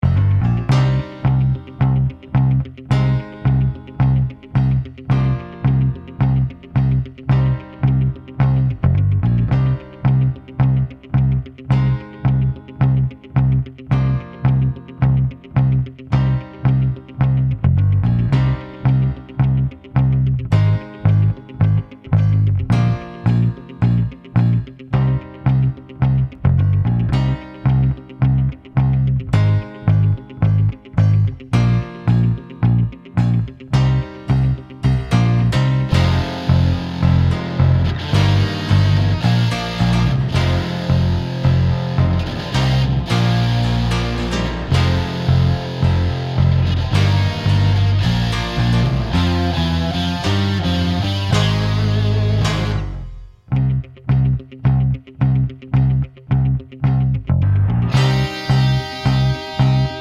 Three Semitones Down Rock 3:58 Buy £1.50